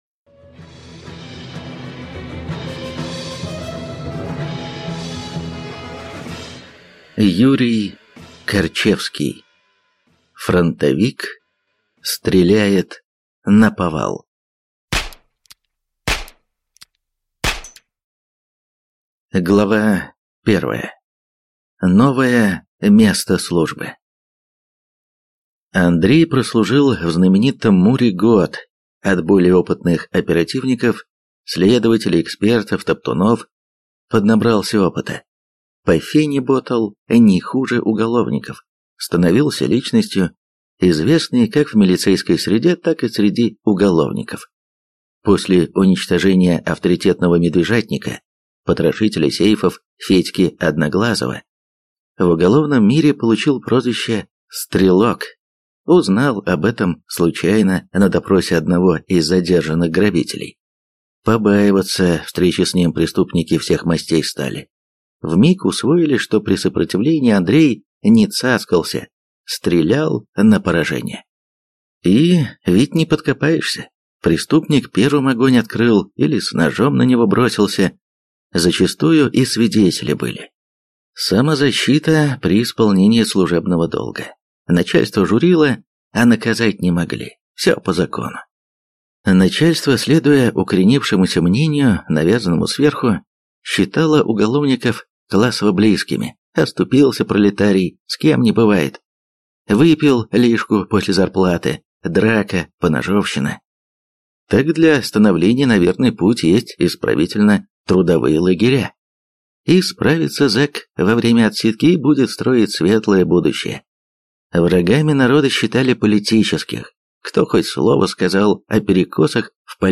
Аудиокнига Фронтовик стреляет наповал | Библиотека аудиокниг